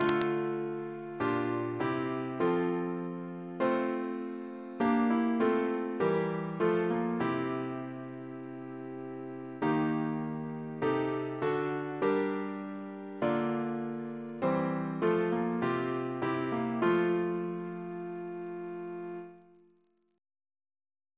Double chant in F Composer: James Turle (1802-1882), Organist of Westminster Abbey Reference psalters: ACB: 53; H1982: S439; OCB: 118 355; PP/SNCB: 57; RSCM: 144